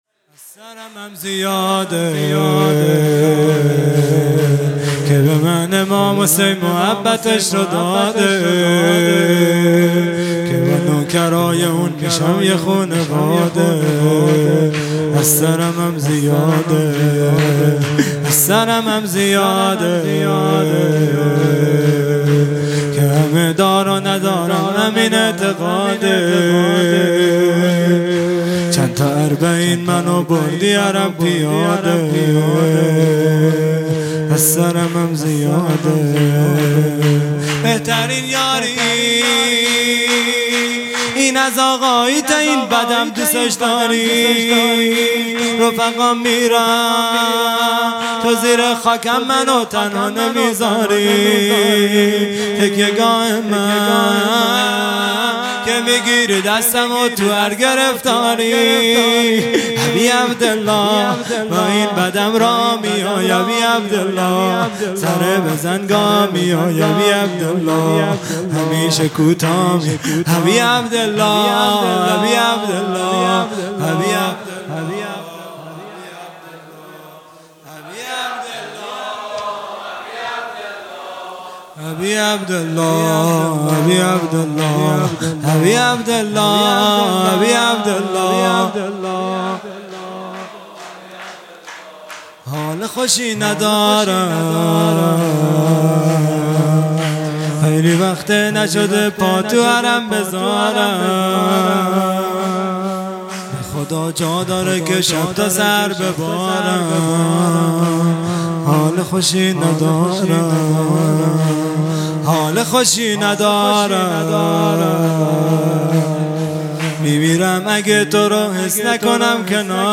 خیمه گاه - هیئت بچه های فاطمه (س) - شور | از سرمم زیاده | 13 مرداد ۱۴۰۱